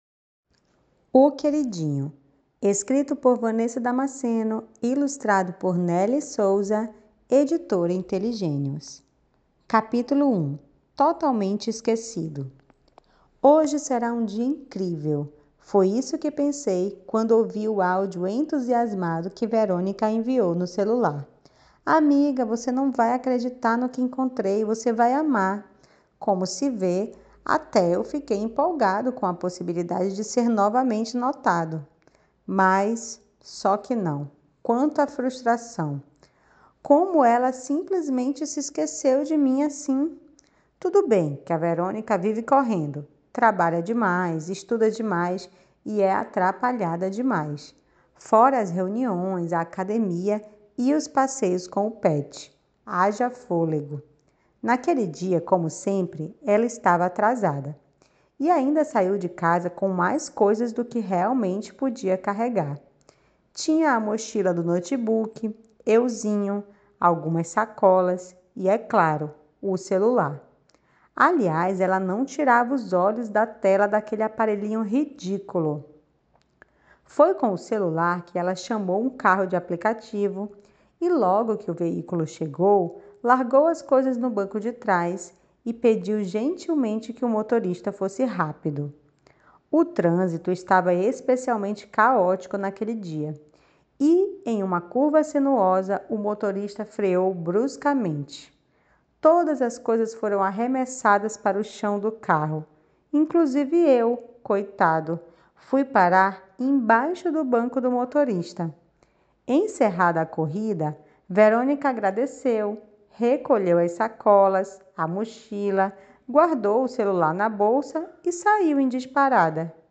Leitura Guiada